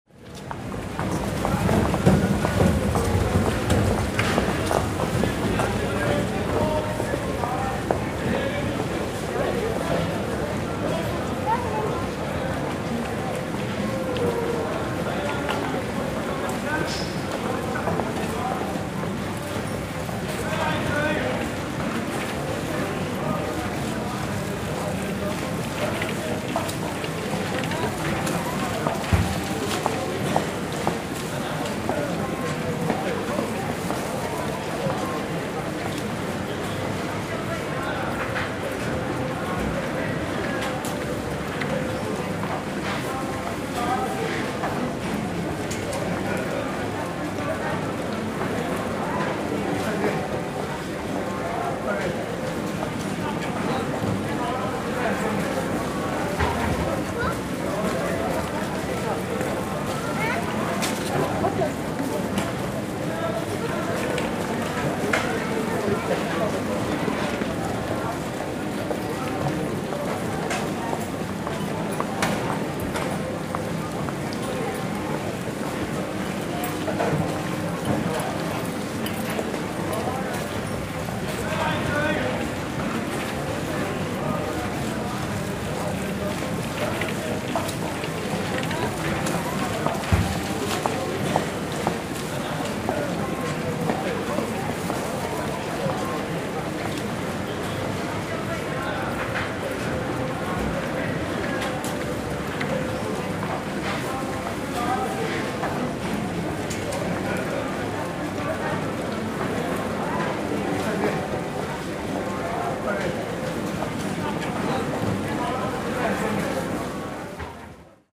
Звуки церкви
Шум толпы в храме